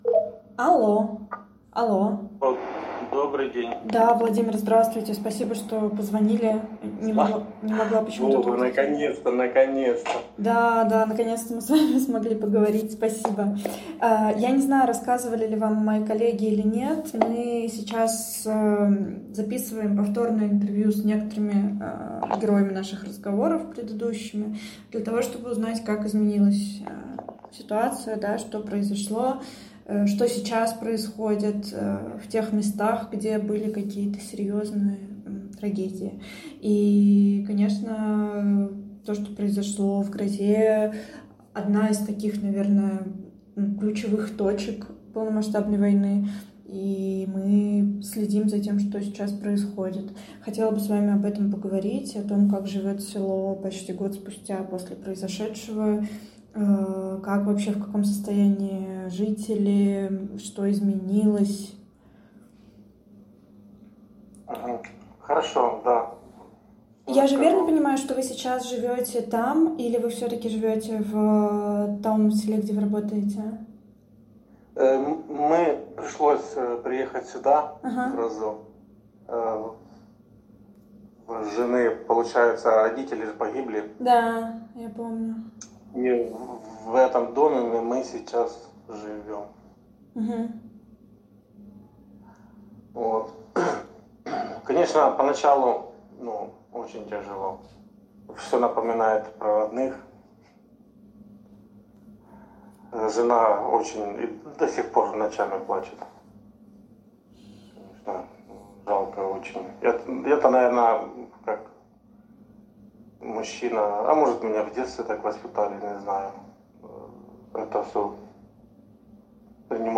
Нас не будет, а он будет напоминать, что прилетела ракета российская и убила полсела — личные свидетельства войны в Украине, архив «Службы поддержки»